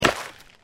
sporecarrier_foot_r02.mp3